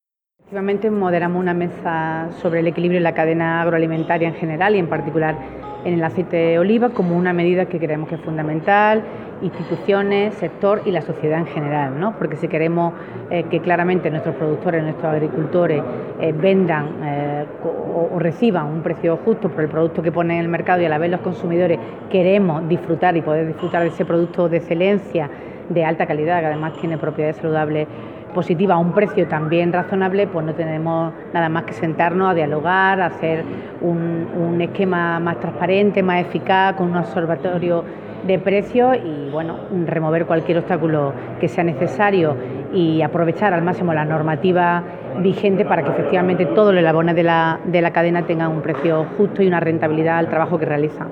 El secretario general de Agricultura y la directora de Industrias han participado en el Simposium Científico-Técnico que se celebra en 'Expoliva'
Declaraciones directora general de Industrias, Rosa Ríos